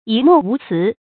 一諾無辭 注音： ㄧ ㄋㄨㄛˋ ㄨˊ ㄘㄧˊ 讀音讀法： 意思解釋： 一口答應，沒有二話。